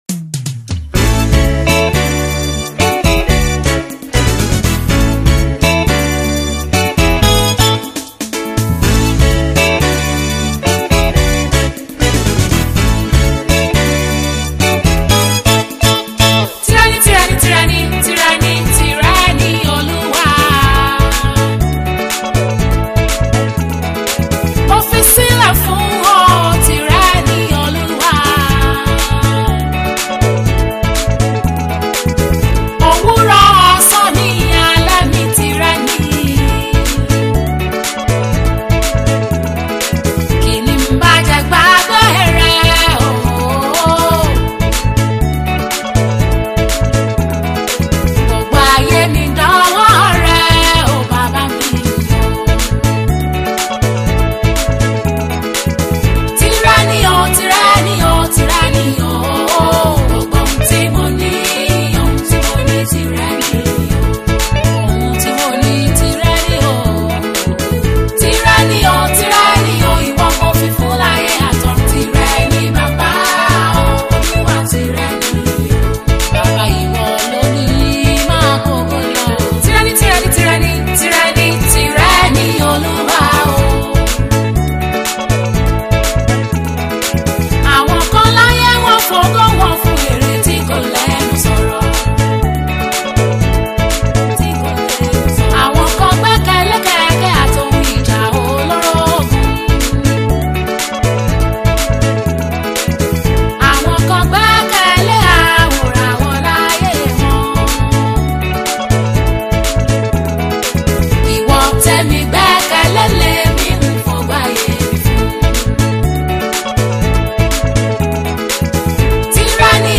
versatile gospel singer